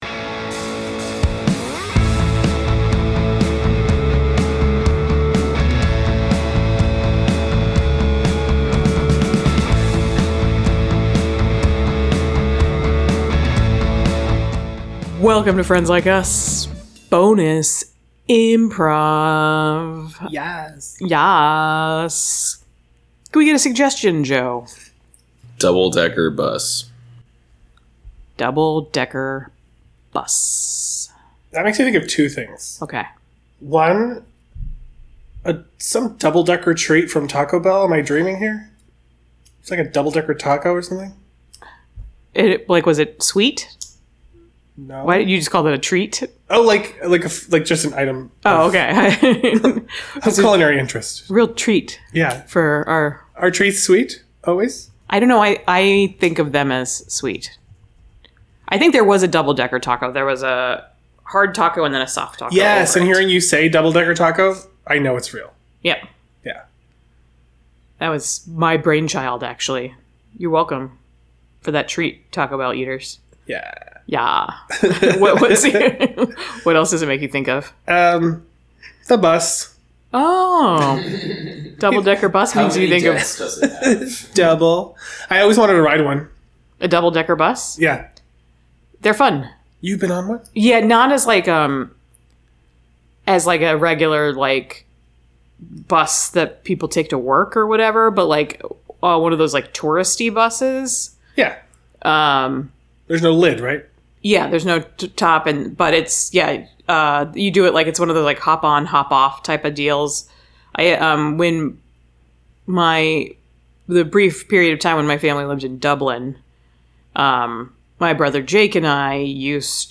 Bonus IMPROV - You Sanitized Your Lips